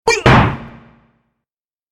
دانلود آهنگ دعوا 10 از افکت صوتی انسان و موجودات زنده
جلوه های صوتی
دانلود صدای دعوای 10 از ساعد نیوز با لینک مستقیم و کیفیت بالا